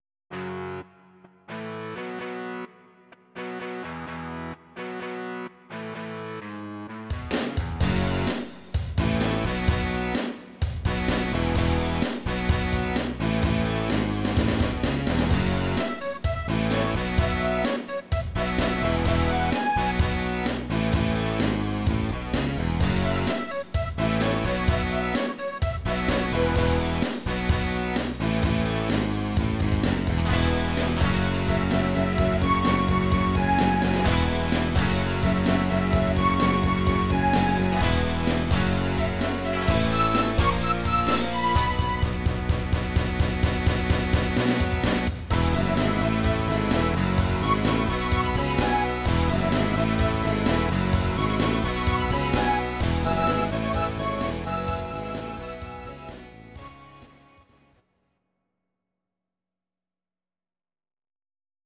Audio Recordings based on Midi-files
Pop, Rock, 2000s